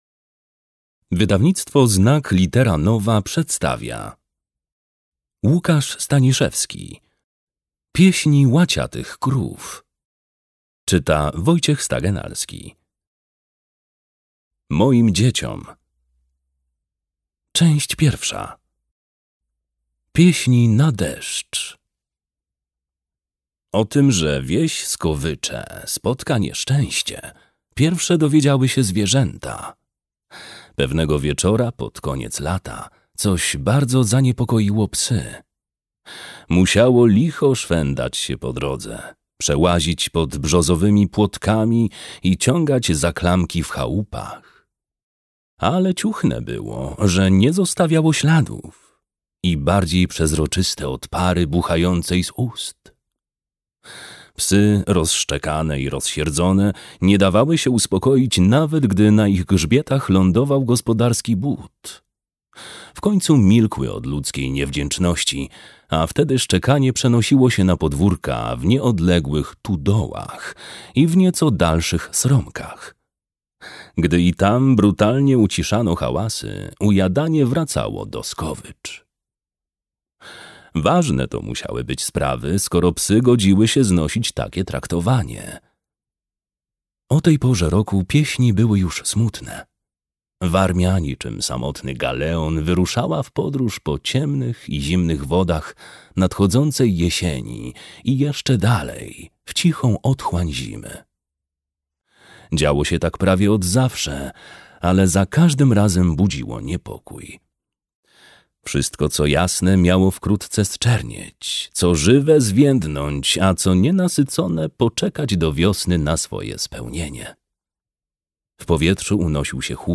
Audiobook Pieśni łaciatych krów